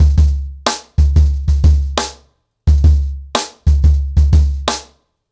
CornerBoy-90BPM.13.wav